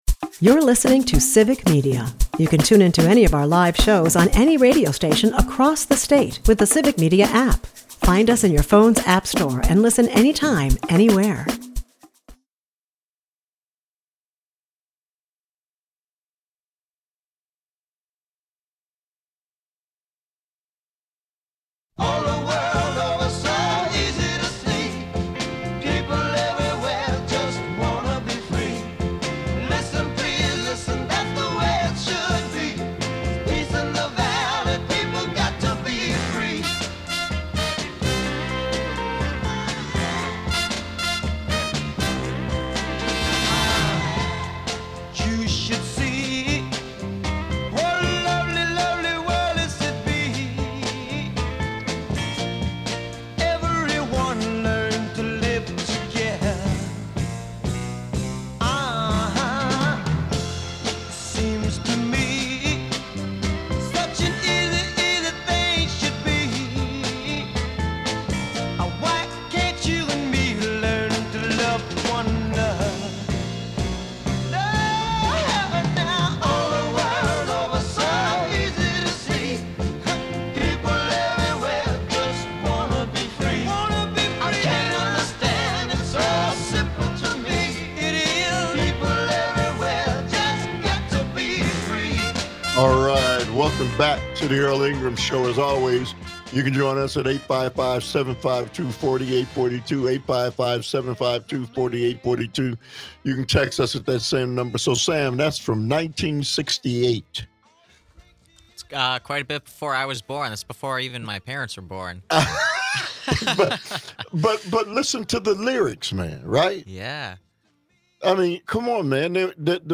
Some callers agree with Maher, but there is division on where the blame falls for things getting this bad.